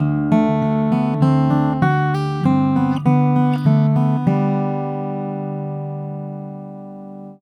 I played a simple Hawaiian vamp and recorded both sources.
Har-Bal computed an EQ set that would bring the pickup closer to the tonal balance of the microphone track. The result was not identical, but much closer.
The Har-Bal adjusted track:
Perhaps a little tweaking with reverb or delay might add a little of the “air” and “body” that are still missing.